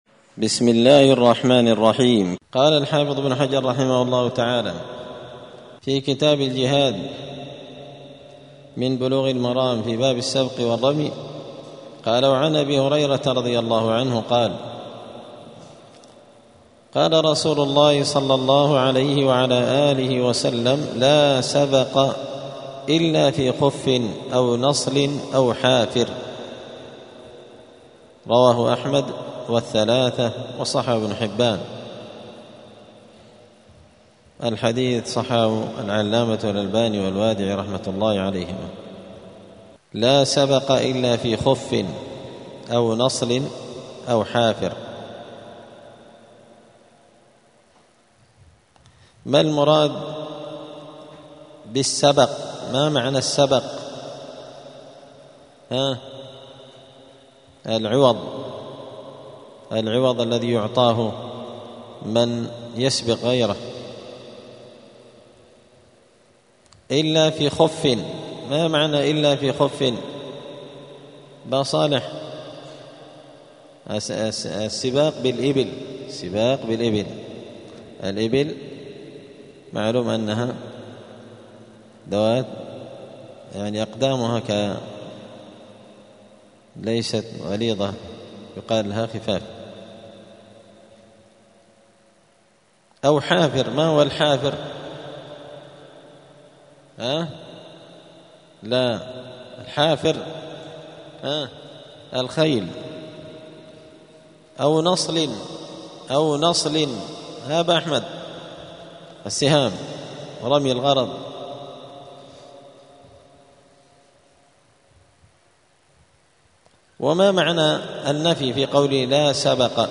*الدرس الثاني والثلاثون (32) {باب السبق والرمي السباق على الخف والحافر والنصل}*